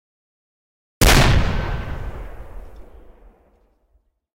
mortar_fire.ogg